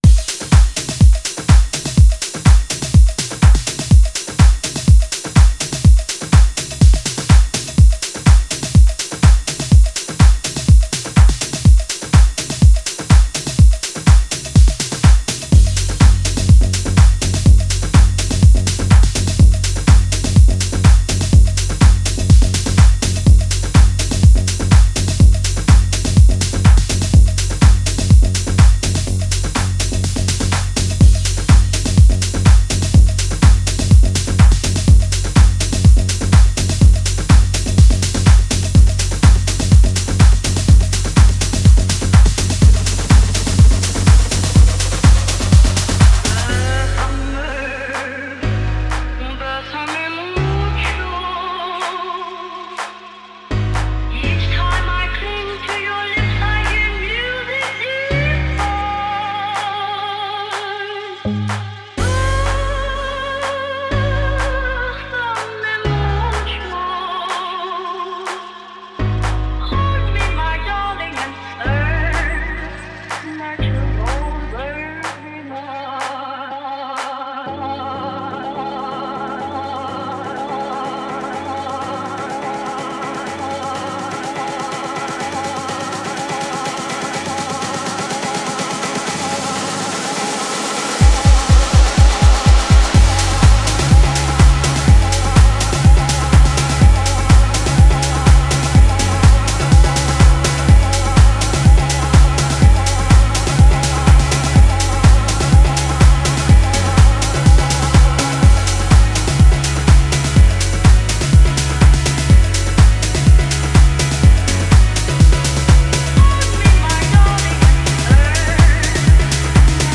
Promo Text:House, Piano House
124bpm House - Piano House